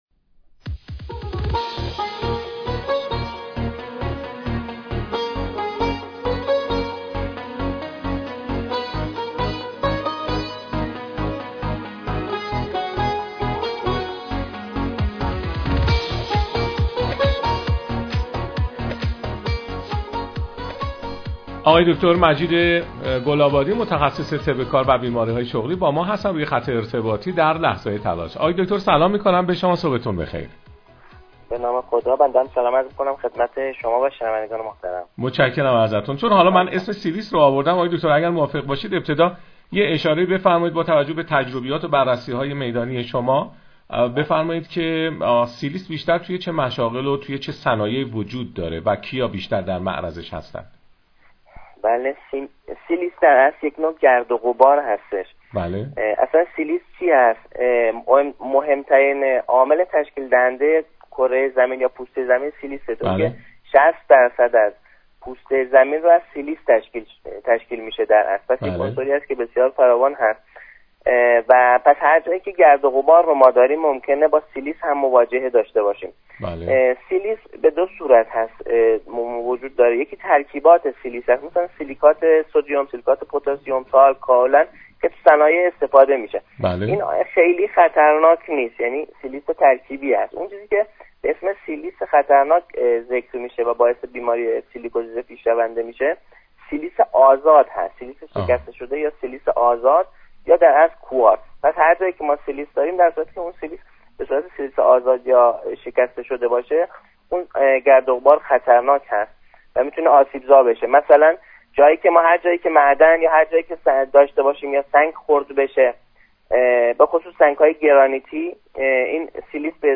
مصاحبه‌های رادیویی